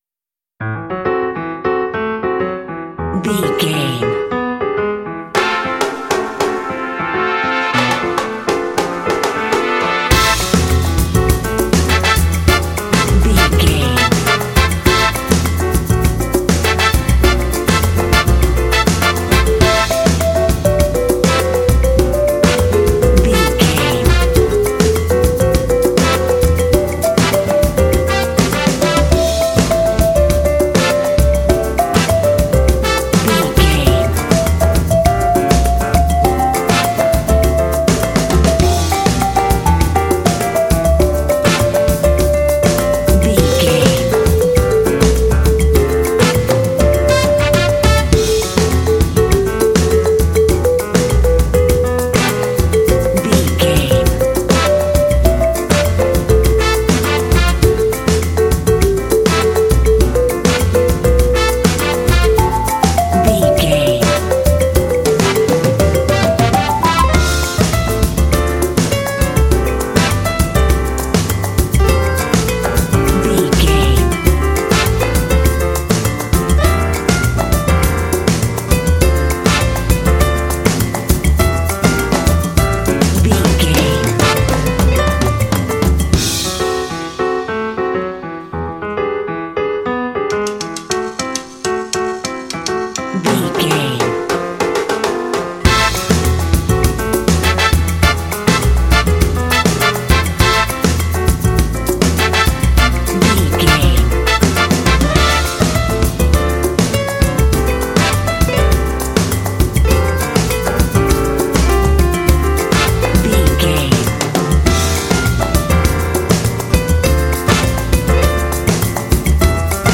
This salsa track is ideal for any latin/Spanish themed game.
Aeolian/Minor
fun
energetic
bright
lively
sweet
horns
brass
piano
electric piano
percussion
80s
latin